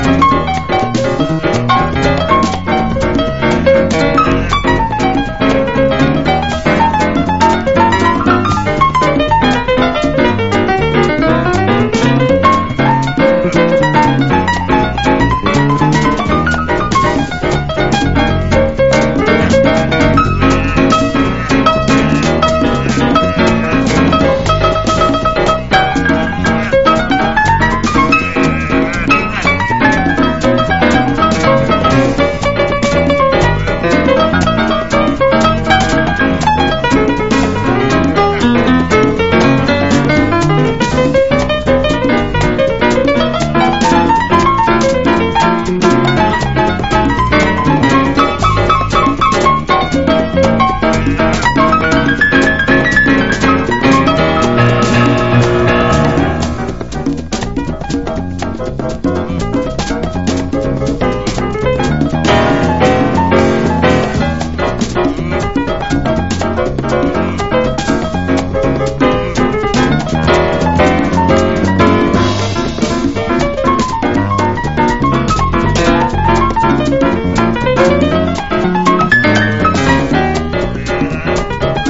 EASY LISTENING / EASY LISTENING / LATIN / LATIN LOUNGE
日本の楽曲をジャズ＆ジャイヴなアレンジでラテン・カヴァーした和モノ